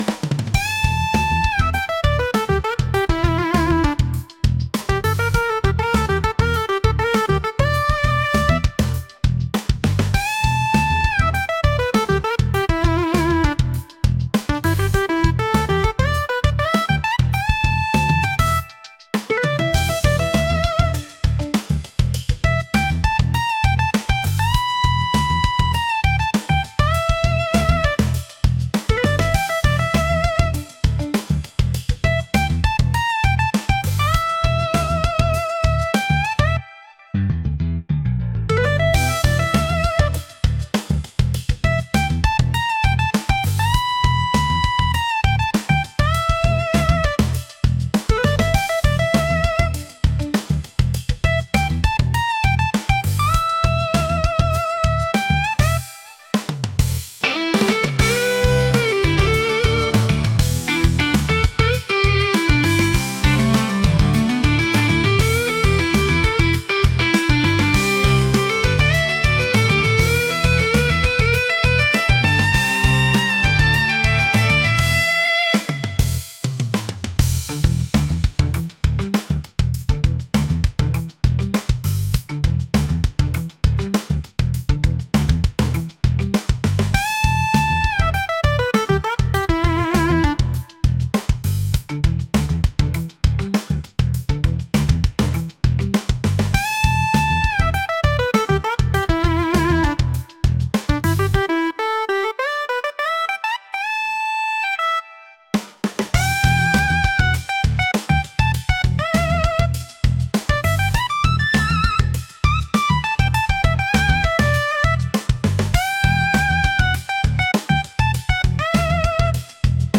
fusion | funky